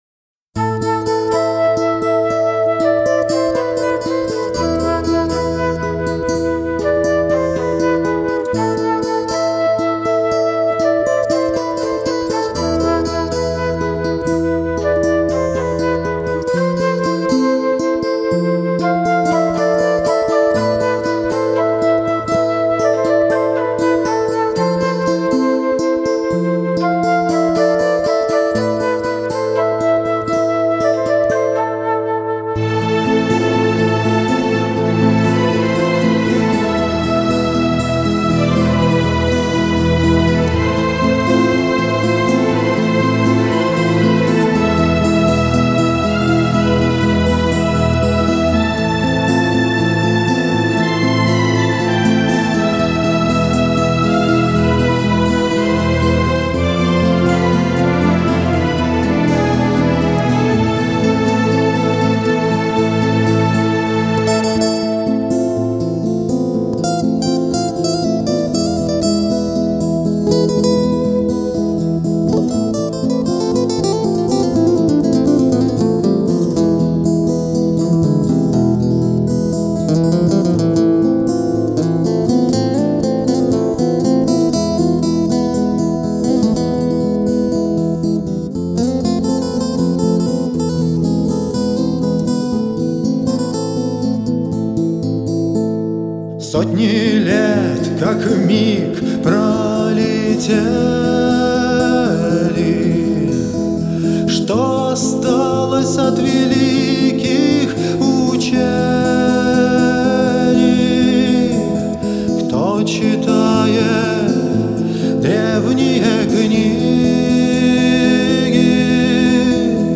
клавишные
все гитары